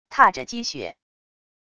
踏着积雪wav音频